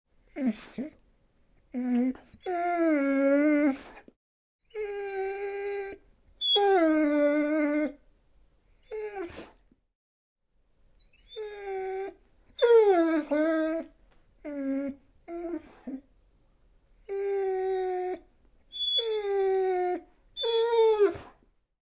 Звуки скуления собаки
Плач одинокой собаки